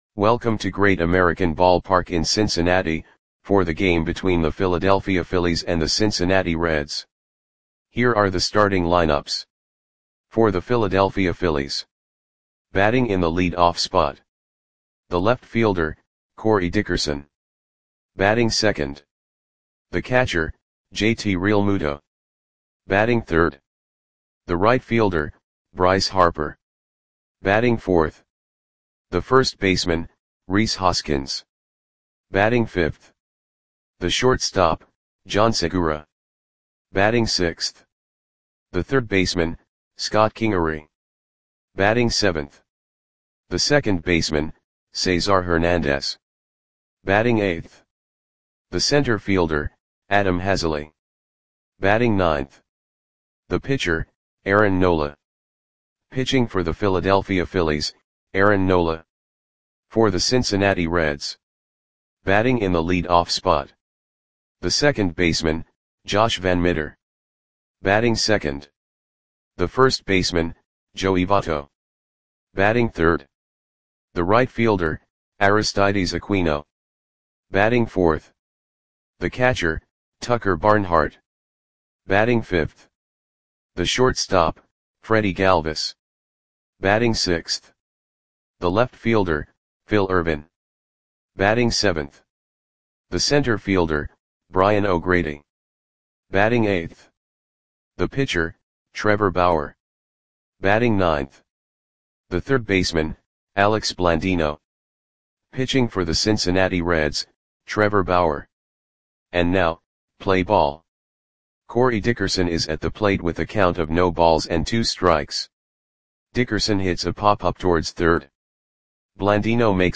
Audio Play-by-Play for Cincinnati Reds on September 4, 2019
Click the button below to listen to the audio play-by-play.